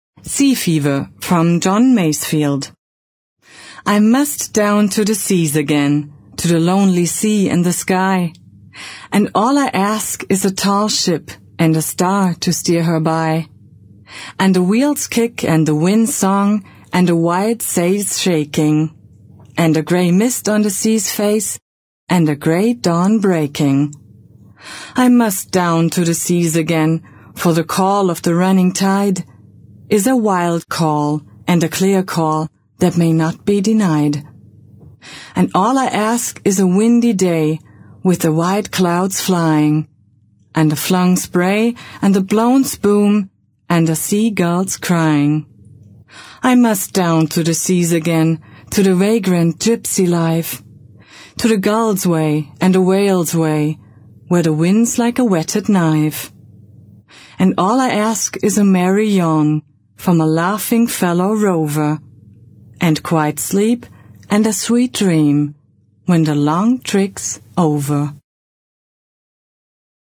Mein großes Plus: absolut sympathische Frauenstimme, da tief & voll Ich freue mich auf Ihren Kontakt!
deutsche Sprecherin für Werbespots, Hörbücher, Emotionales, Telefonansagen.
Sprechprobe: eLearning (Muttersprache):
german female voice over talent